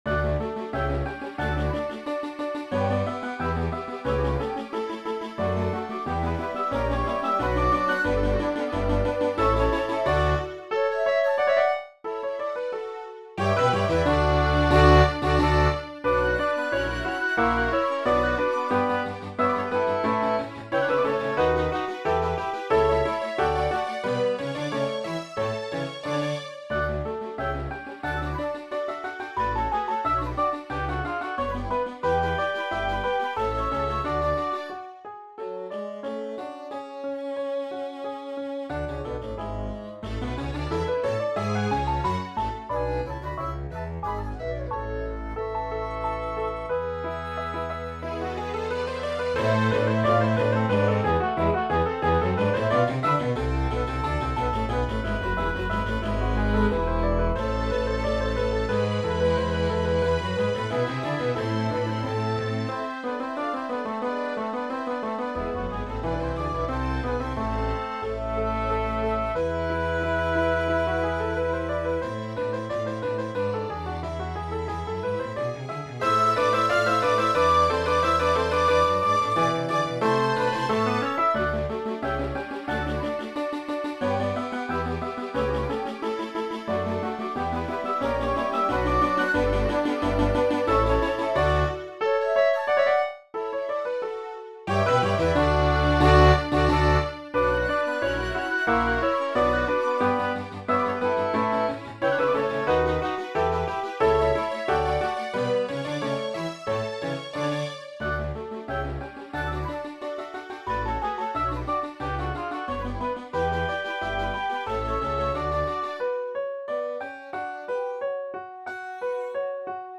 (D-Dur)